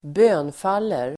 Uttal: [²b'ö:nfal:er]